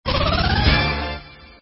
刚进入挑战音效.mp3